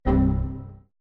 error.mp3